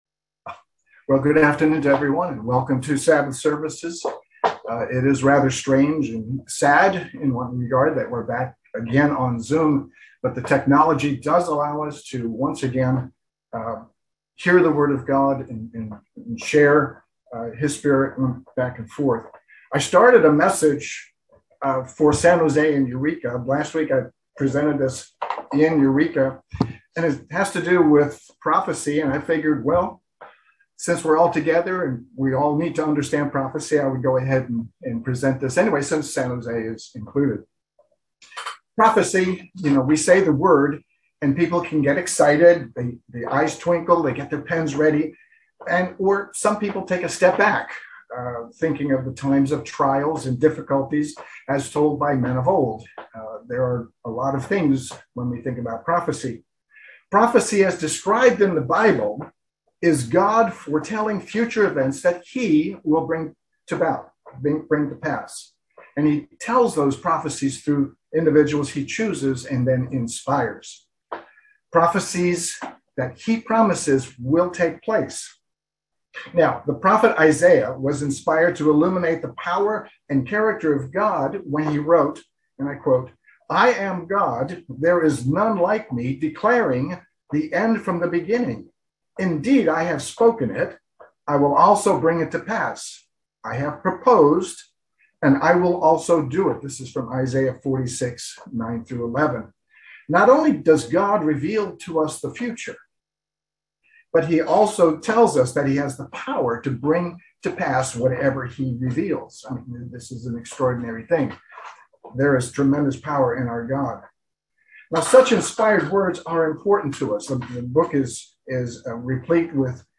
Sermons
Given in Petaluma, CA San Francisco Bay Area, CA